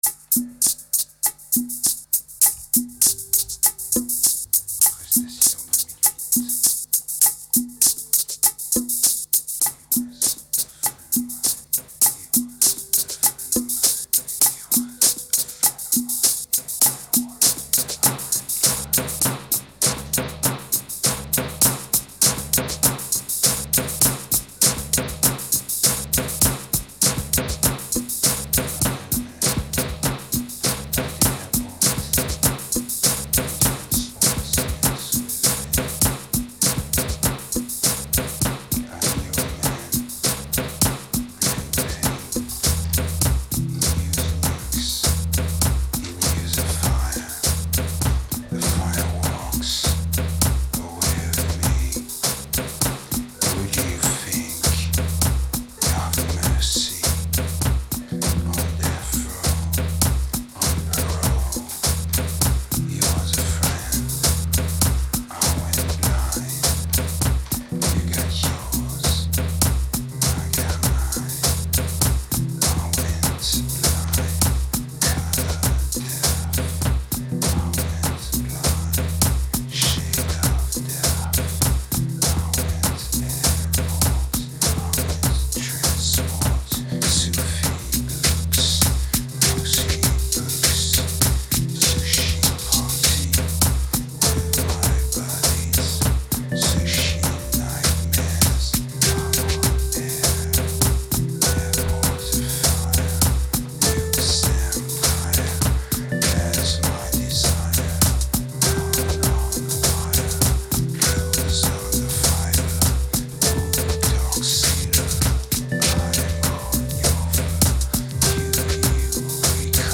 Vocals are still poor but a bit more directed.